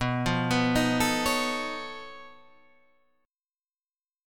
B9b5 chord